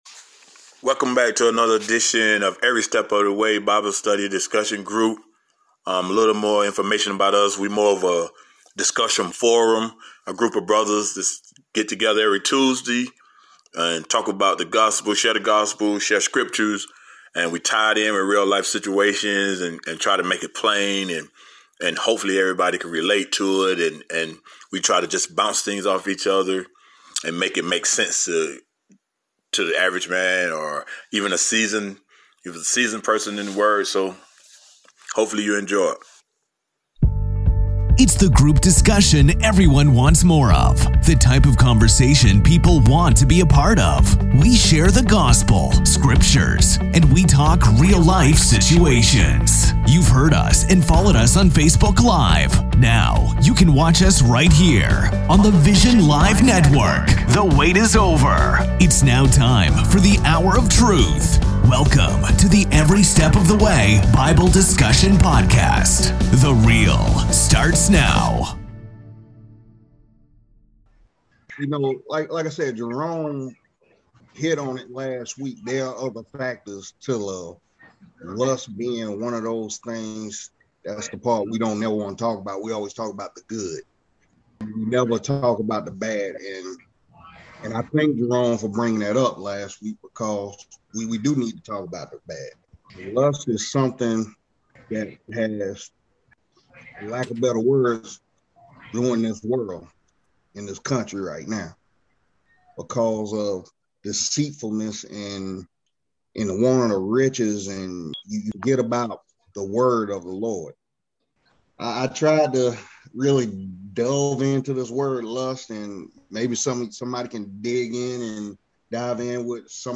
The Every Step of the way Panel talks why lust is one of the most harmful things.